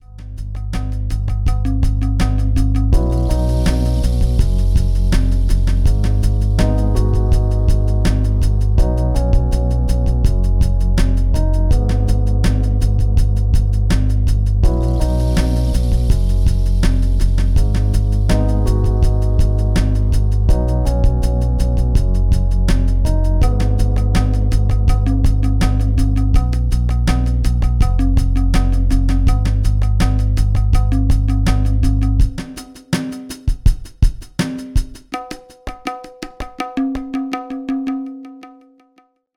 (0:39) Some dark triphop